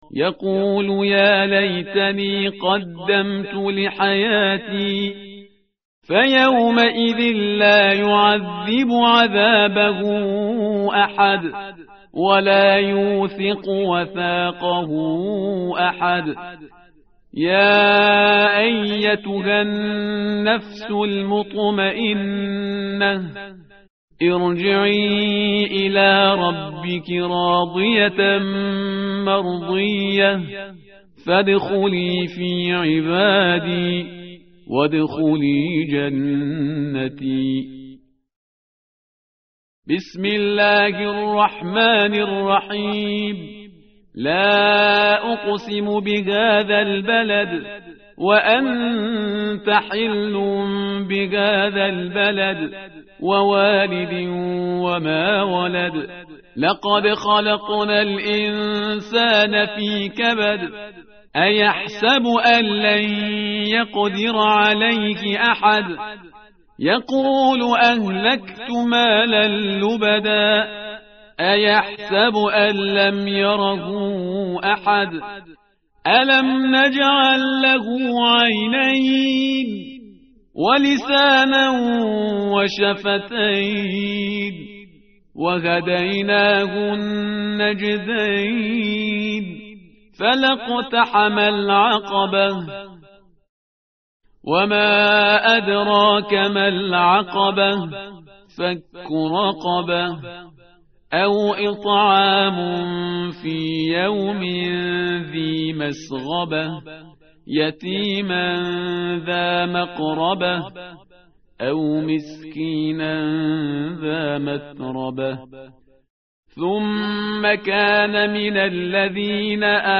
متن قرآن همراه باتلاوت قرآن و ترجمه
tartil_parhizgar_page_594.mp3